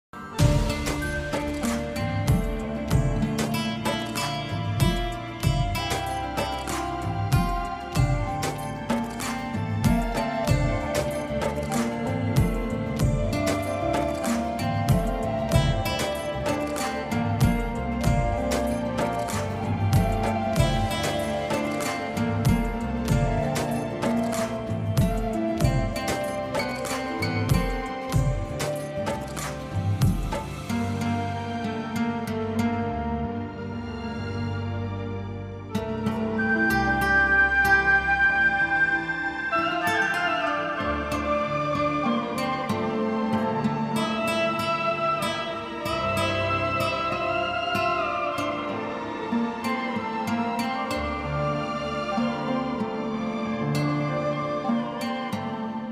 Drama Background Music